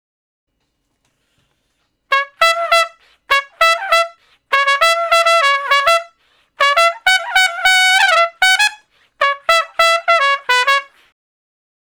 087 Trump Straight (Db) 01.wav